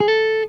SLIDESOLO5.wav